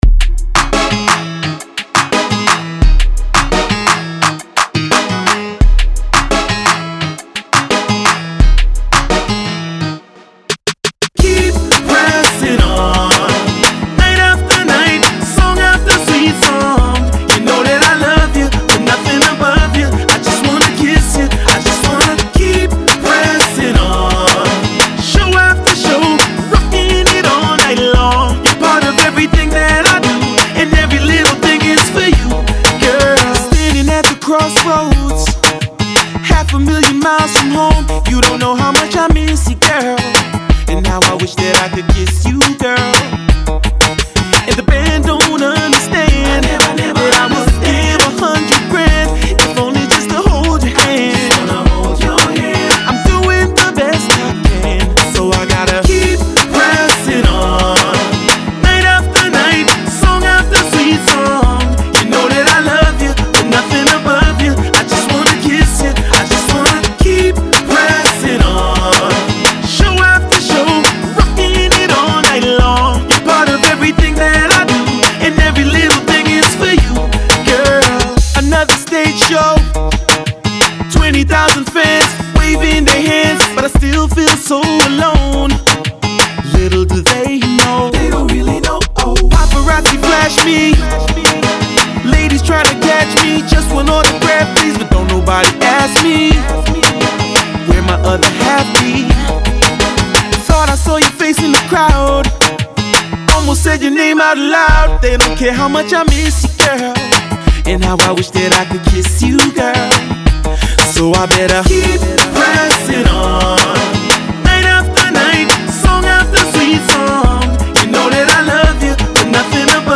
Saint Lucian music.
Genre: Dancehall